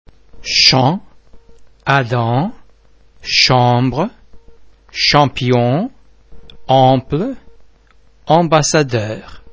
en em an am vowel-base similar to ong in (pong)
·[am]
en_champs.mp3